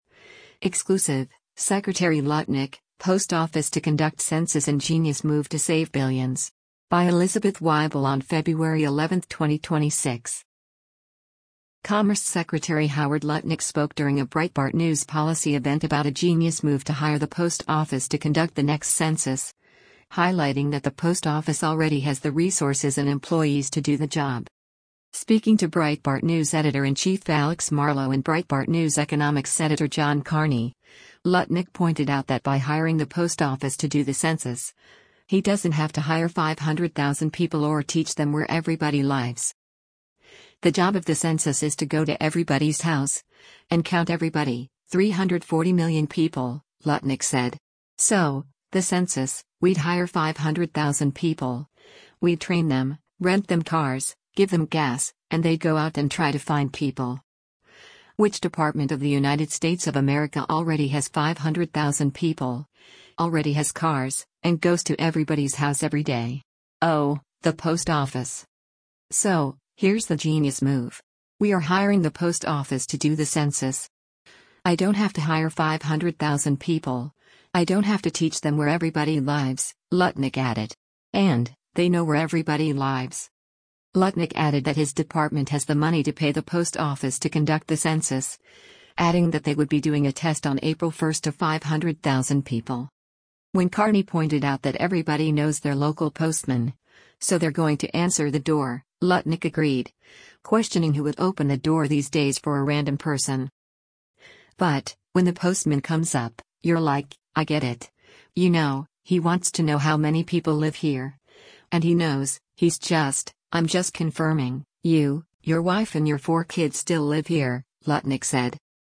Commerce Secretary Howard Lutnick spoke during a Breitbart News policy event about a “genius move” to hire the Post Office to conduct the next Census, highlighting that the Post Office already has the resources and employees to do the job.